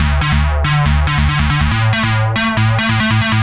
FR_ZBee_140-D.wav